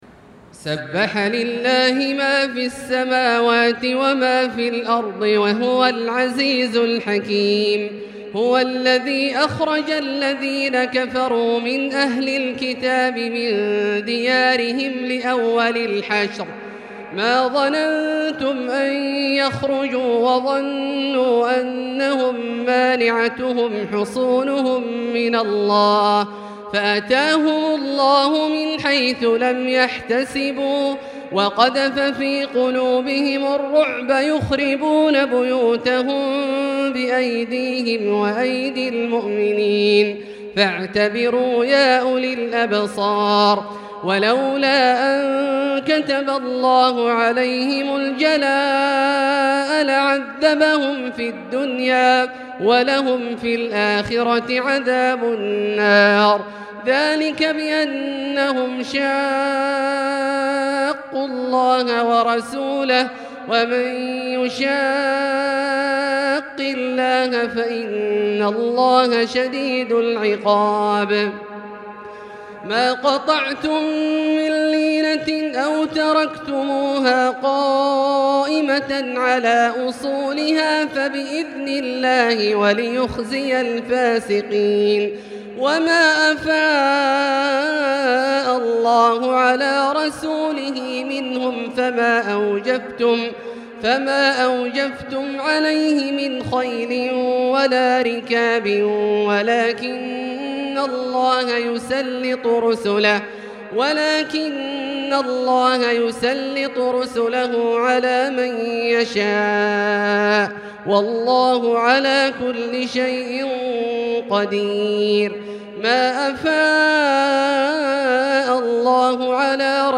المكان: المسجد الحرام الشيخ: فضيلة الشيخ عبدالله الجهني فضيلة الشيخ عبدالله الجهني الحشر